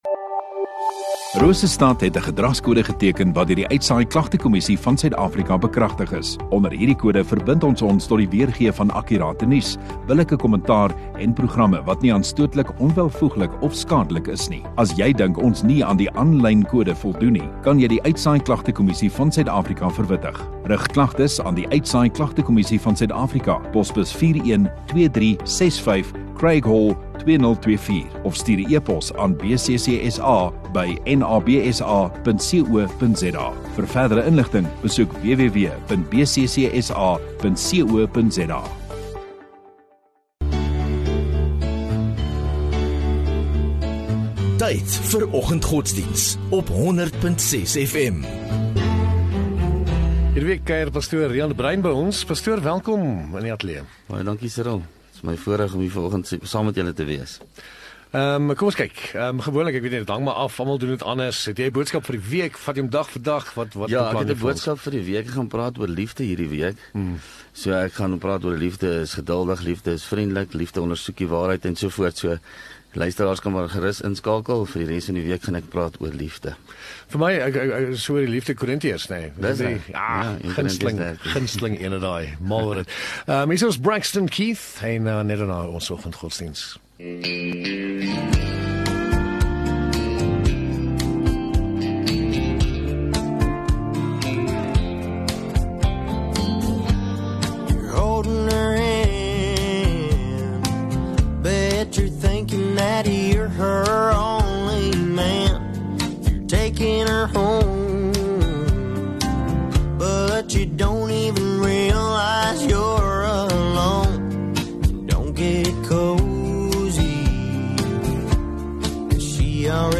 View Promo Continue Install Rosestad Godsdiens 15 Jul Maandag Oggenddiens